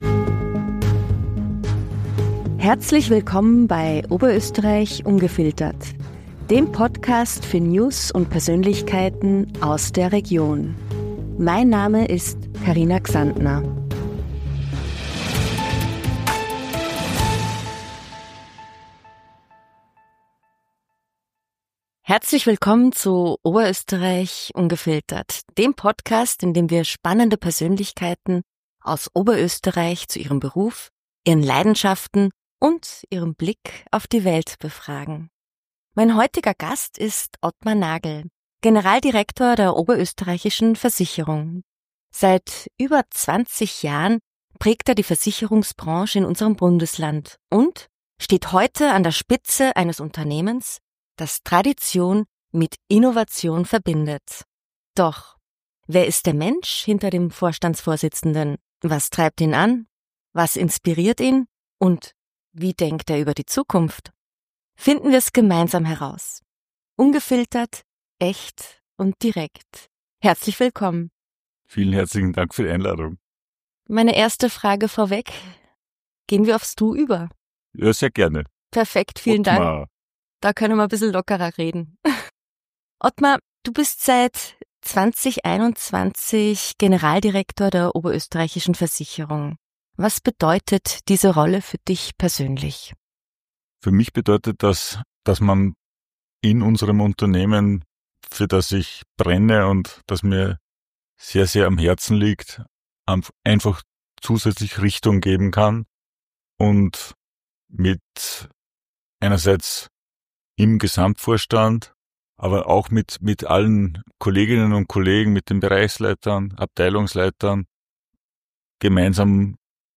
In einem persönlichen Gespräch reflektiert er darüber, wie seine frühere Karriere als Wirtschaftsprüfer und seine Leidenschaft für Musik seine berufliche Entwicklung beeinflusst haben.